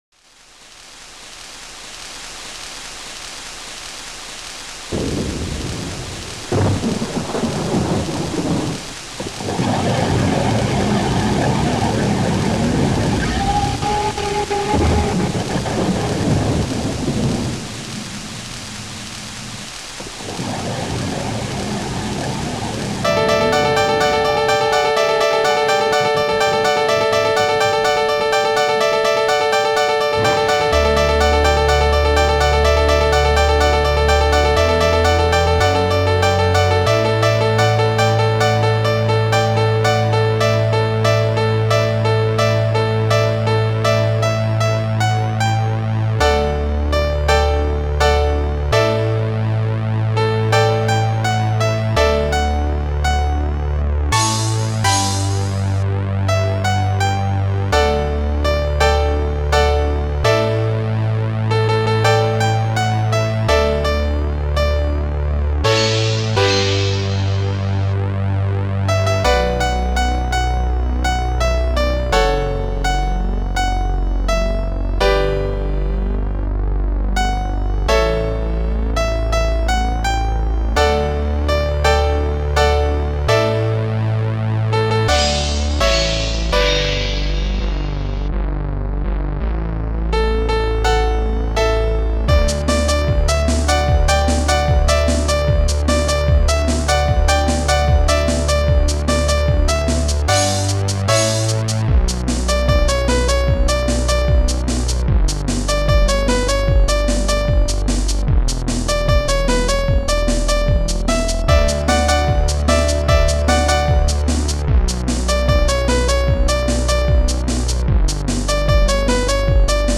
crash1
bassdrum1
shaker
guitar heavy rock
car engine
thunder
rain
half time beat